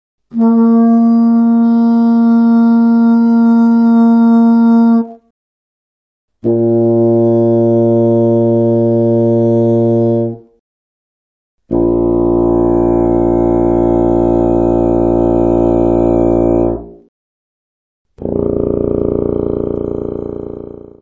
Contrafagote:
Som de contrafagote.